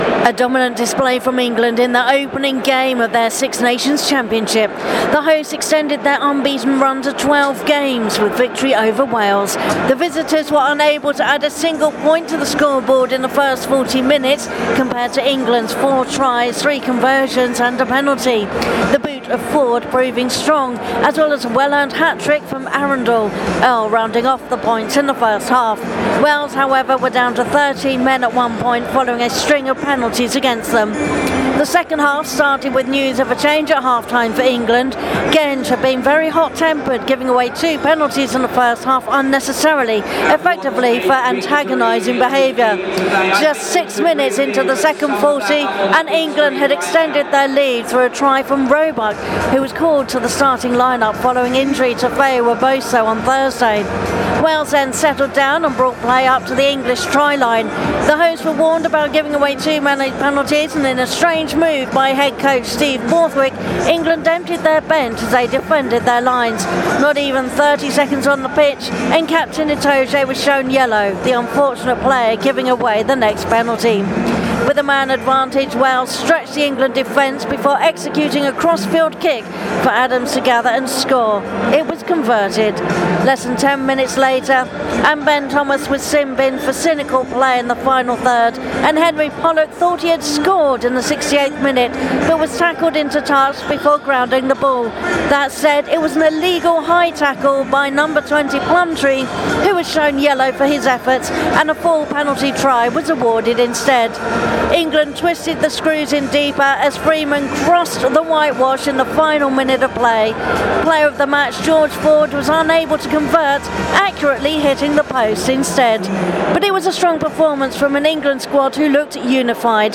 reports from a sold-out Allianz Stadium in Twickenham as the full-time whistle blew: England 48 – 7 Wales.